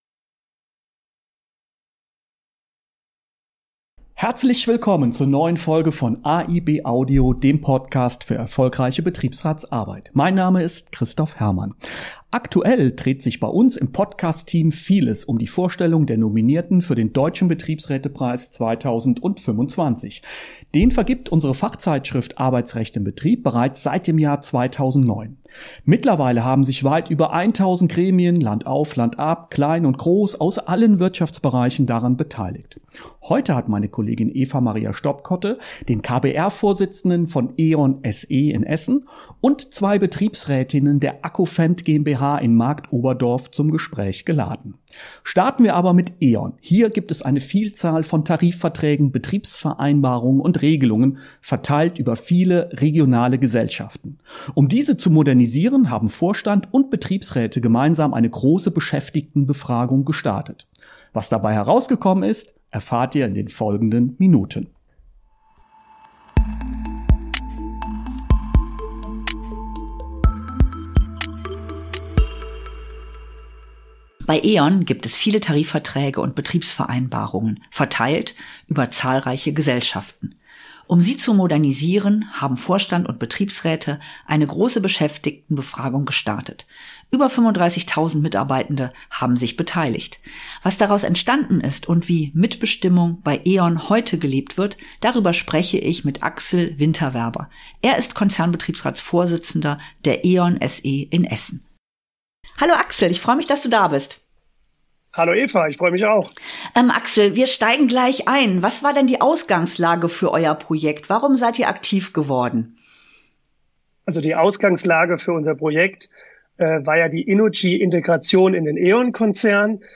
Und wie gewinnt ein Betriebsrat das Vertrauen der Belegschaft zurück? In dieser Folge sprechen Betriebsrät:innen von E.ON SE und AGCO Fendt über neue Wege der Mitbestimmung – von einer groß angelegten Beschäftigtenbefragung bis hin zu offensiver Öffentlichkeitsarbeit auf allen Kanälen.